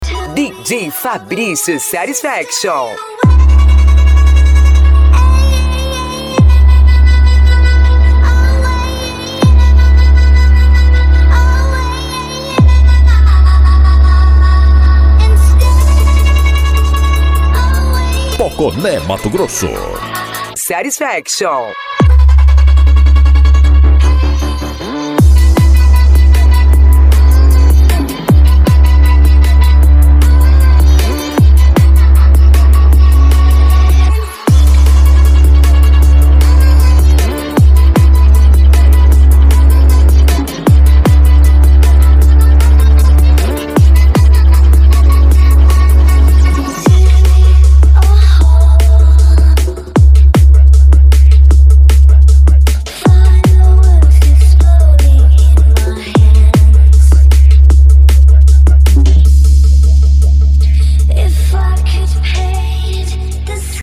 Bass
Funk
Mega Funk
Remix